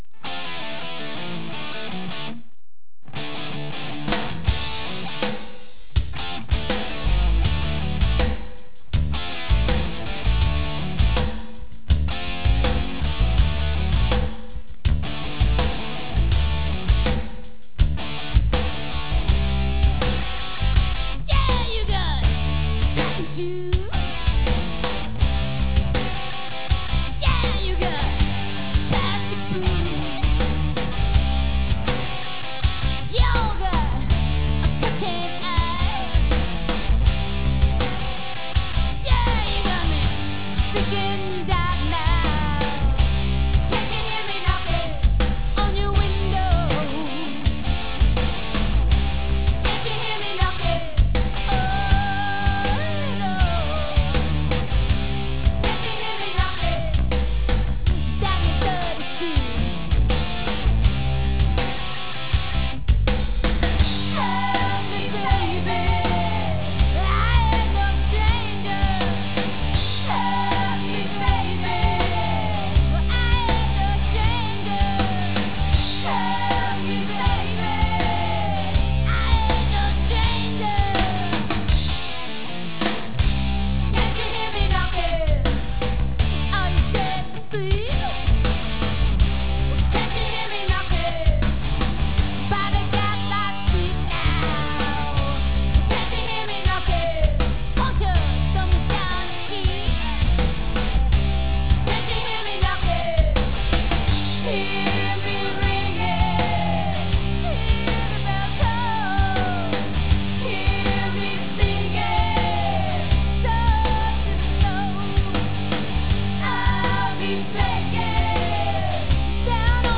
high spirited rock and roll band
Studio   The Lanes, Allston
Lead Vocal/Guitar
Drums/Vocals
Lead Guitar/Vocals
Bass/Vocals